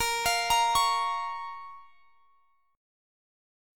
A#m Chord (page 3)
Listen to A#m strummed